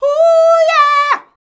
HUUYEAH L.wav